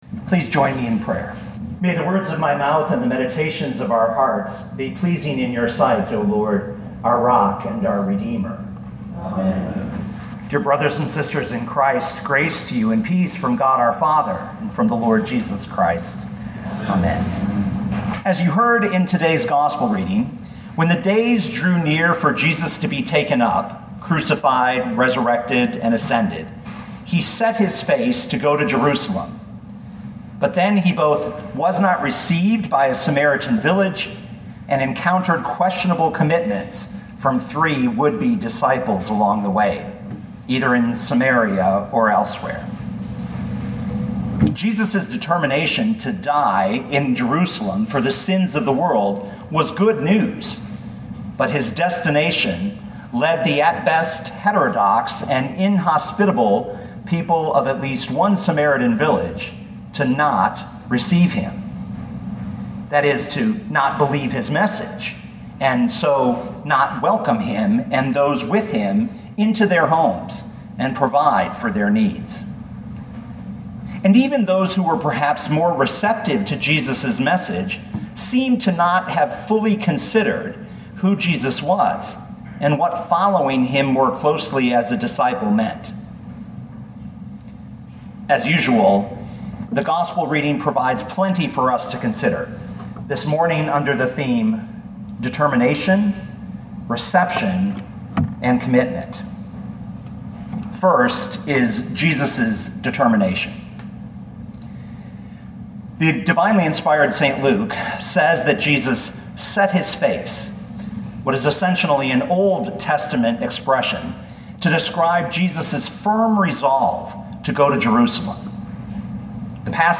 2019 Luke 9:51-62 Listen to the sermon with the player below, or, download the audio.